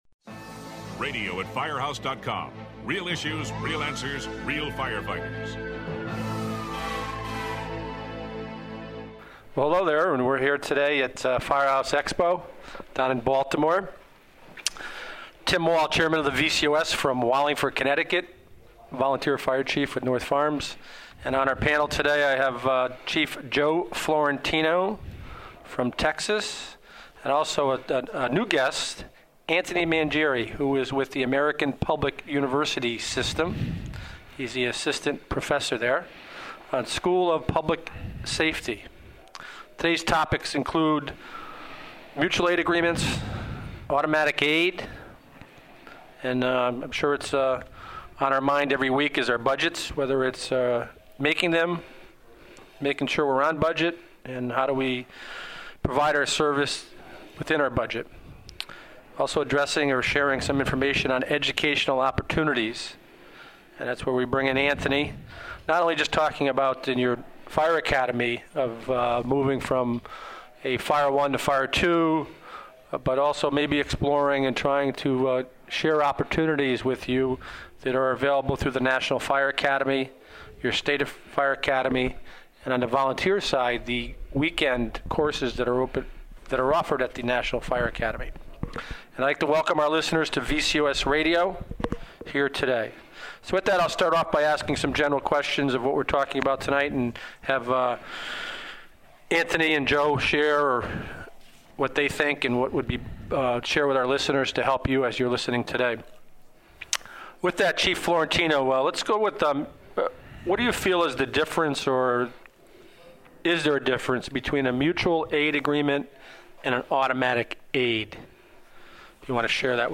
The panel discusses the ins and outs of mutual and automatic aid policies, such as coordinated training, relationship building and insurance coverage concerns.